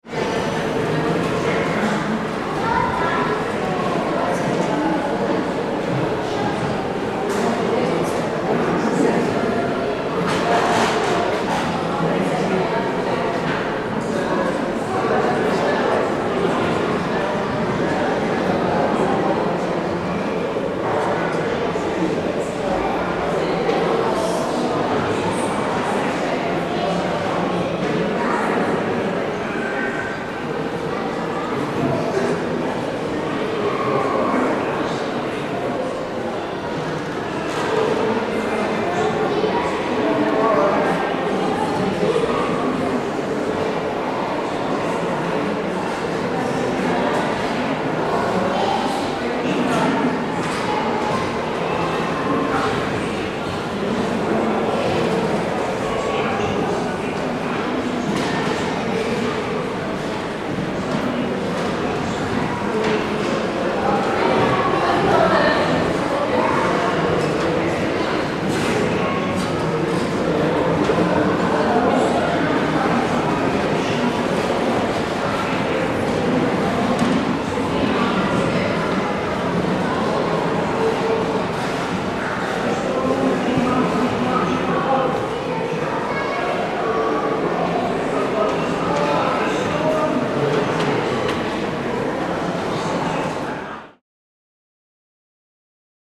Kelvingrove museum atmosphere
The atmosphere of the Kelvingrove art museum in Glasgow. People walking, talking, children playing. The amazing reverberation of the building makes steps sound a bit like treated percussion, at times. Minimum editing (fade in/out and EQ).